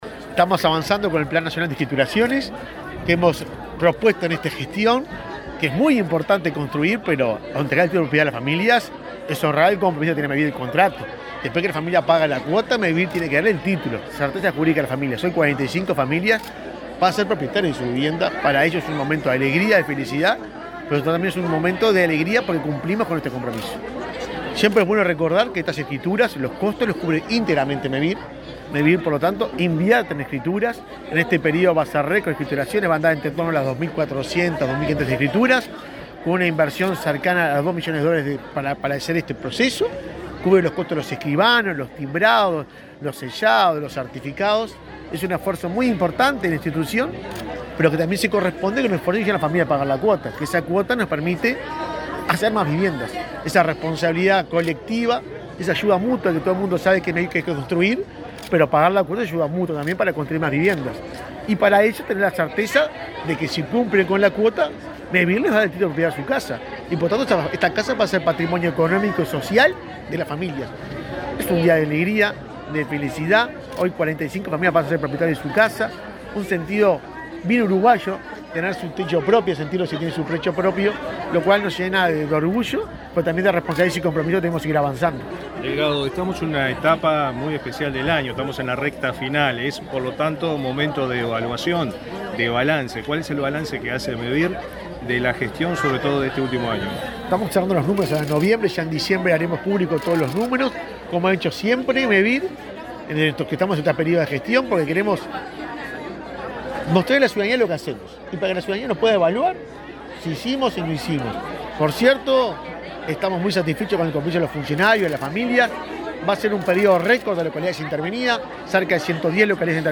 Declaraciones del presidente de Mevir, Juan Pablo Delgado | Presidencia Uruguay
Declaraciones del presidente de Mevir, Juan Pablo Delgado 21/11/2023 Compartir Facebook X Copiar enlace WhatsApp LinkedIn El presidente de Mevir, Juan Pablo Delgado, dialogó con la prensa en Florida, antes de participar del acto de escrituración de 50 viviendas en la localidad de Goñi.